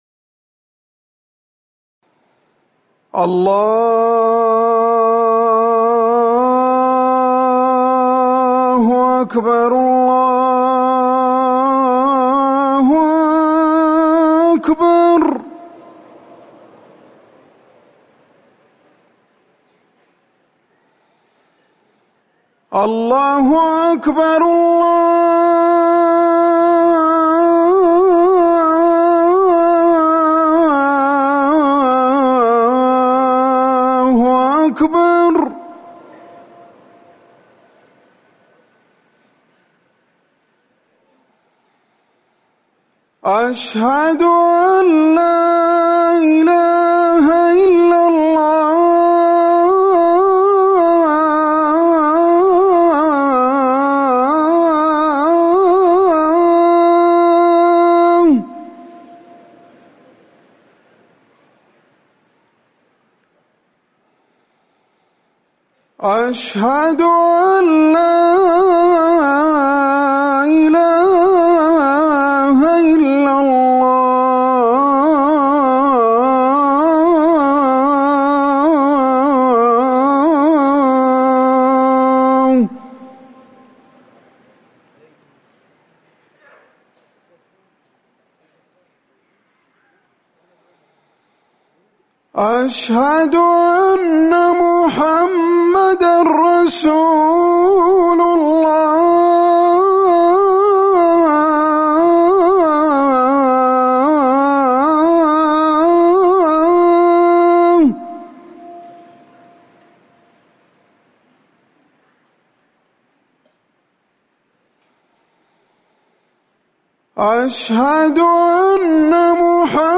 أذان الظهر
المكان: المسجد النبوي